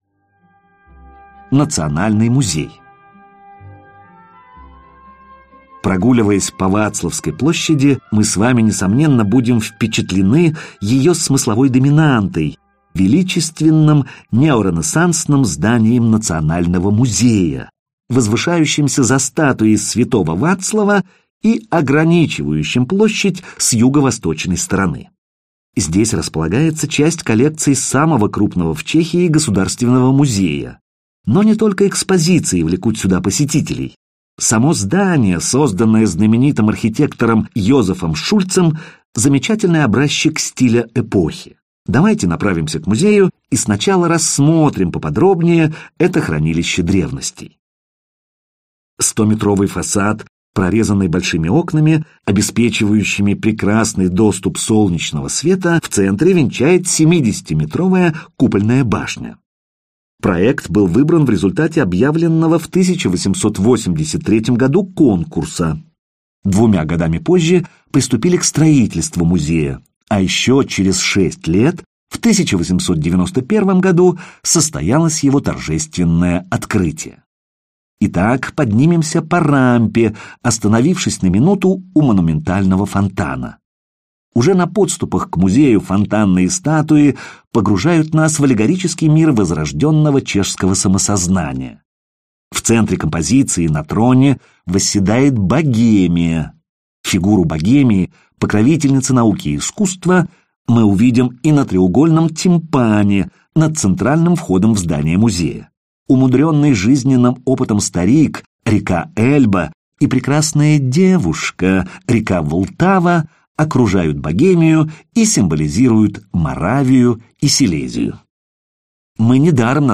Аудиокнига Прага | Библиотека аудиокниг